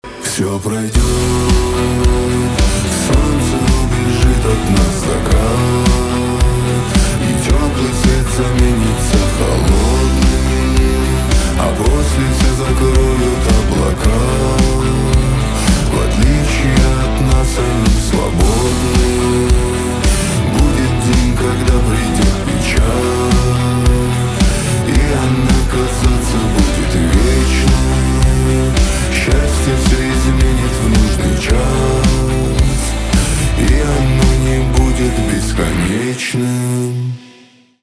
русский рок , гитара , барабаны , печальные
чувственные